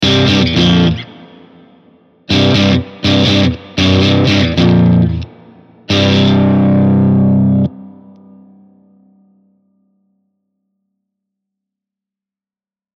Ich habe nur Werks-Presets verwendet, außer etwas Hall und dem t-Racks Brickwall Limiter als Übersteuerungsschutz wurden keine Effekte verwendet.
Zuerst ein kurzes Riff, das auf Powerchords beruht.
5. OR50 Preset „Crispy Crunch“
05_riff_or50_crispycrunch.mp3